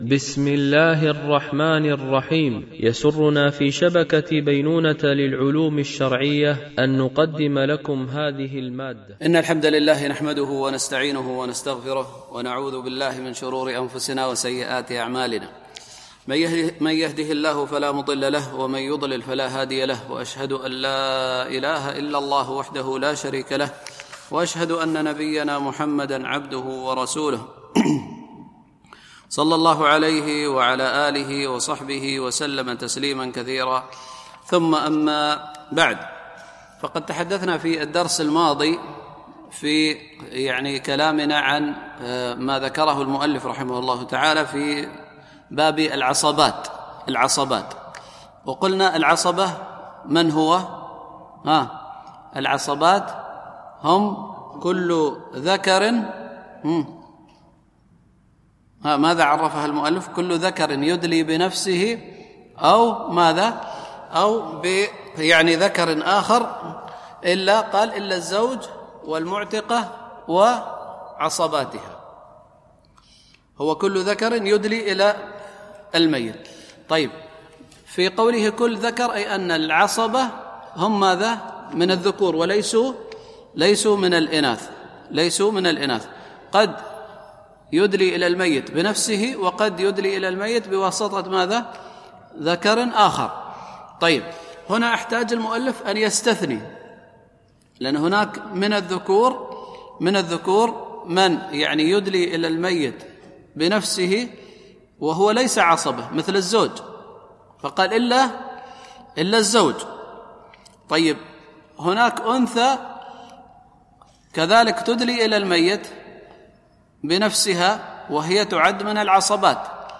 شرح عمدة الفقه ـ الدرس 103 (كتاب الفرائض)